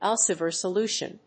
アルセバー